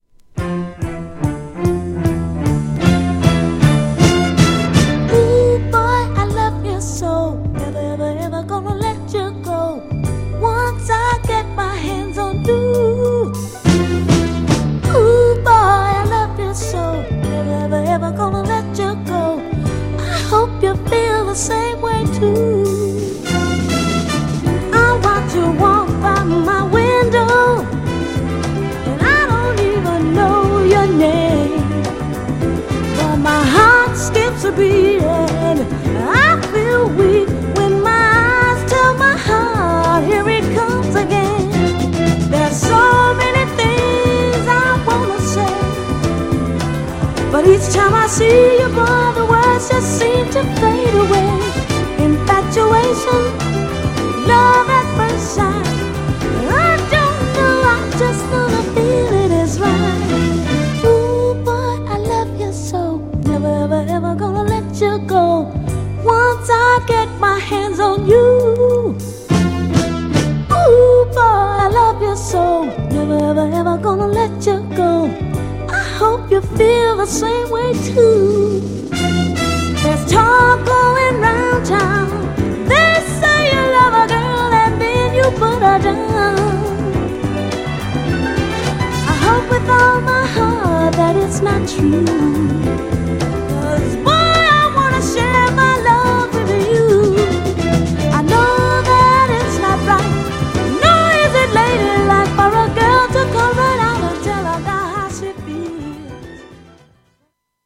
GENRE Dance Classic
BPM 106〜110BPM
GROOVY